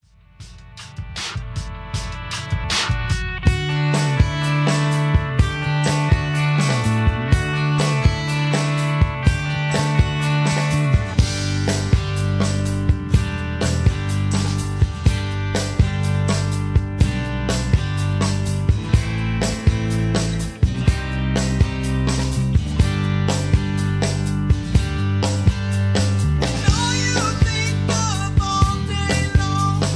karaoke, studio tracks, sound tracks, backing tracks, rock